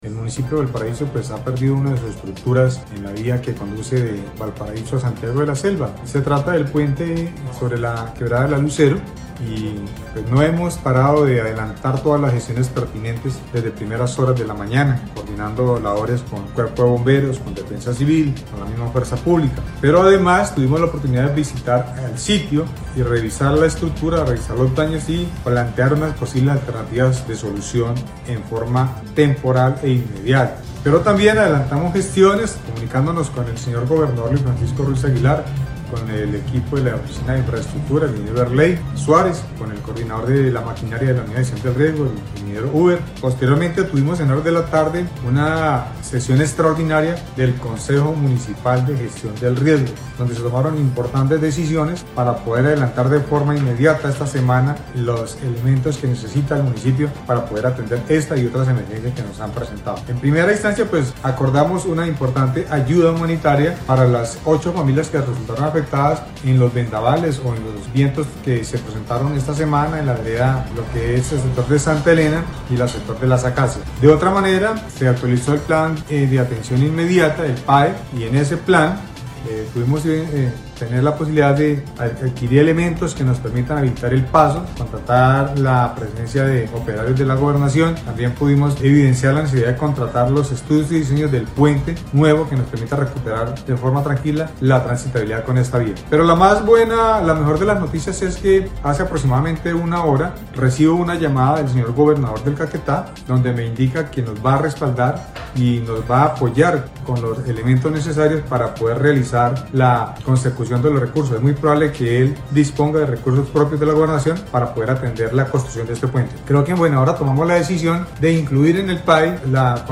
Así lo dio a conocer el alcalde del municipio de Valparaíso, Arbenz Pérez Quintero, al indicar que, luego de reunir de manera extra ordinaria al consejo municipal de gestión del riesgo, se acordó avanzar en la ejecución del plan de atención inmediata, con el cual se podrá contratar los estudios y diseños previos de una nueva estructura.
02_ALCALDE_ARBENZ_PEREZ_PUENTE.mp3